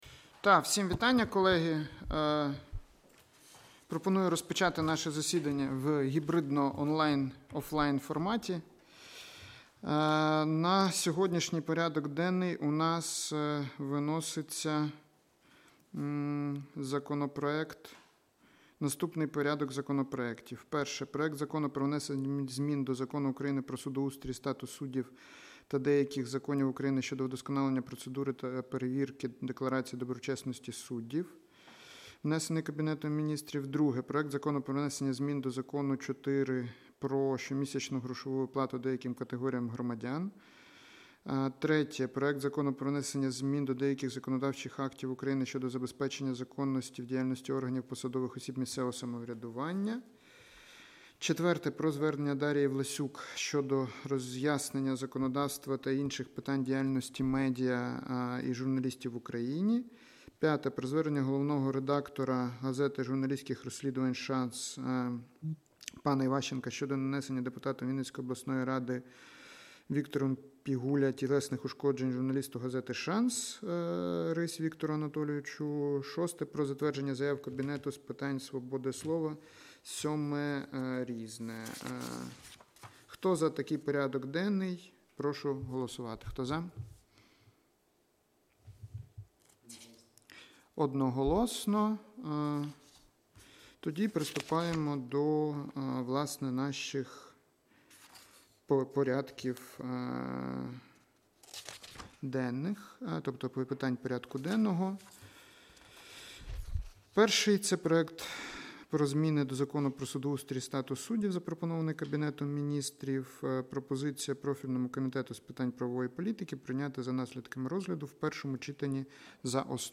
Аудіозапис засідання Комітету від 17 квітня 2025р.